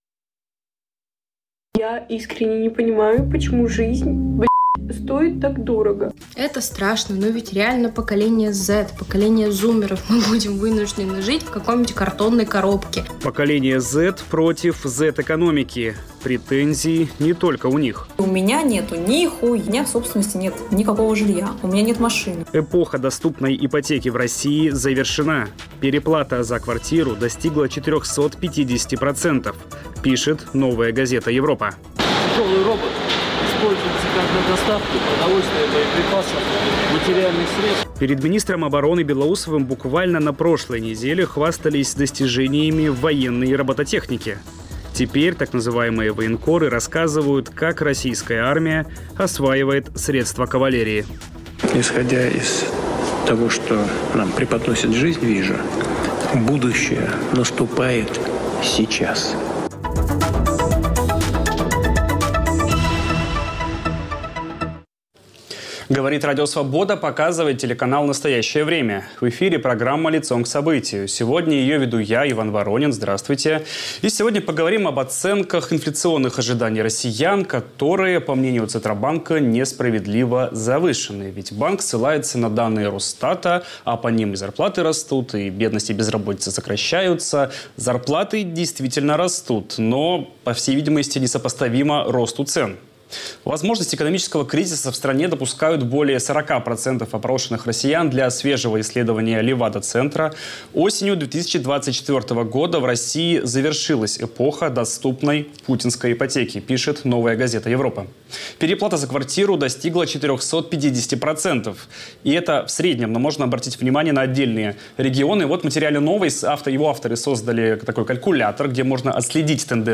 Центробанк просит не считать инфляцию: пятикратные переплаты за квартиры и новый рост цен. Об этом говорим с политологом